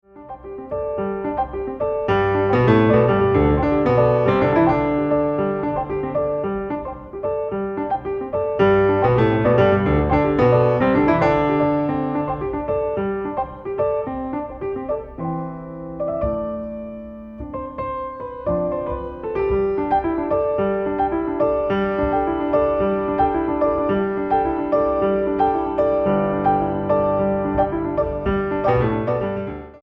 piano
clarinetto